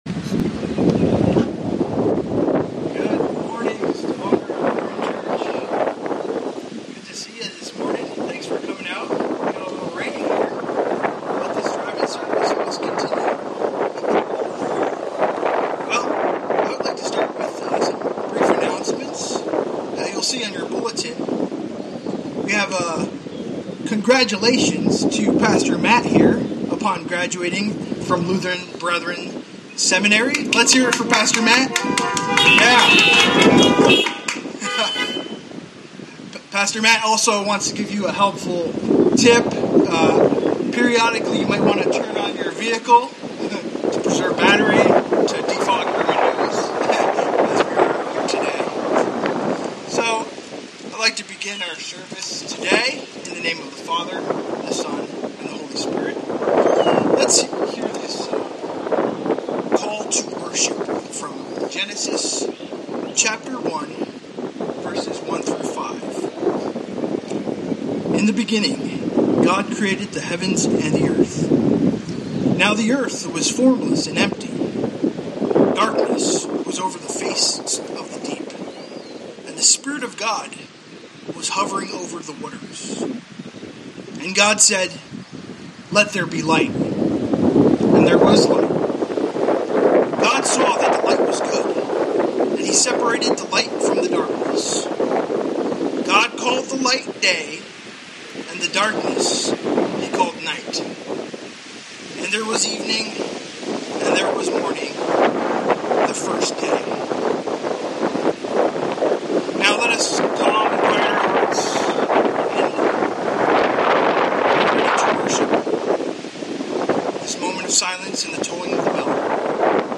From Series: "Sunday Worship"
Sunday Worship - We apologize for the poor audio in parts...the wind!!